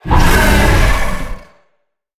Sfx_creature_hiddencroc_flinch_03.ogg